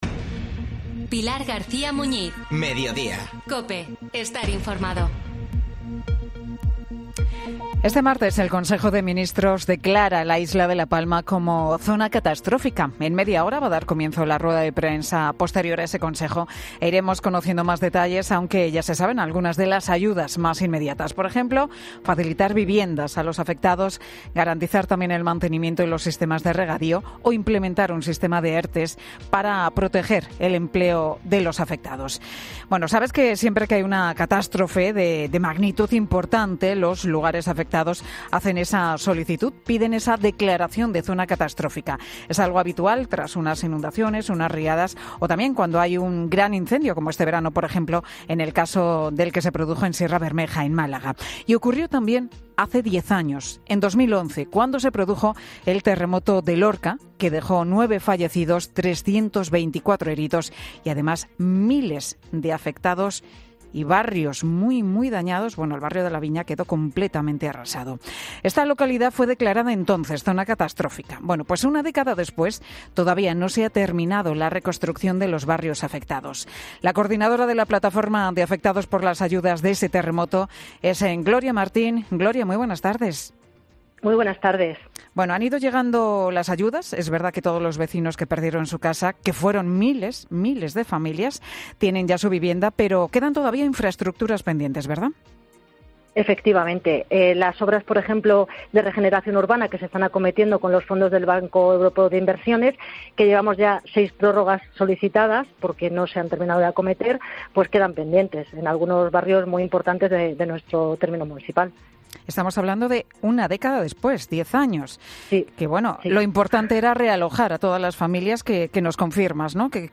En Mediodía COPE hemos hablado con